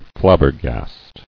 [flab·ber·gast]